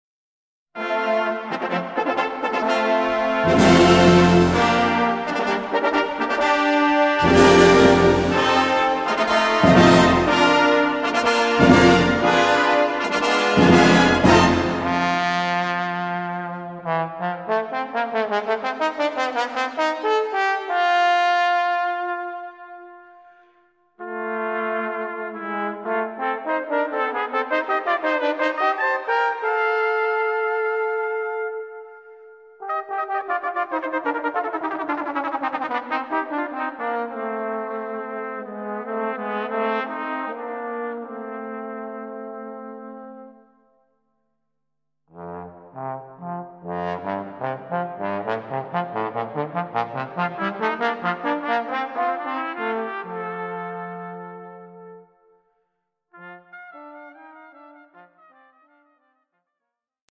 Gattung: Solo für diverse Instumente und Blasorchester
Besetzung: Blasorchester
Solostück für Trompete und Posaune (Horn).